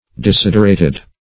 Desiderated - definition of Desiderated - synonyms, pronunciation, spelling from Free Dictionary
Desiderate \De*sid"er*ate\, v. t. [imp. & p. p. Desiderated;